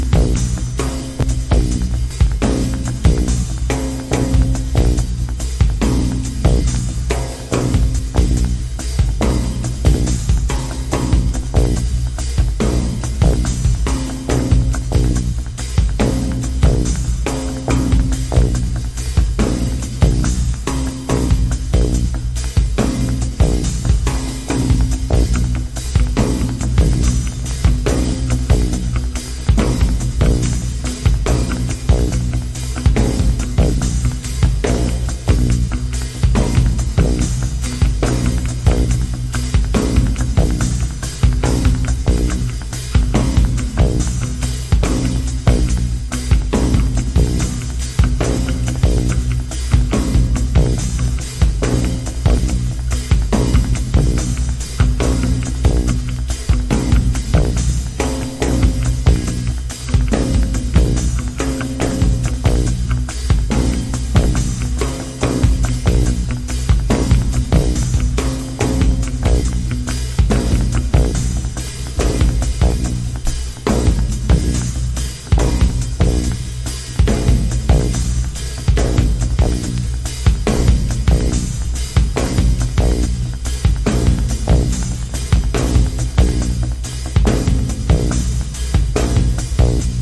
インダストリアルなパーカッションをベースに次第に楽器音が重複肥大化していくテクノ+ポストロックなサウンド。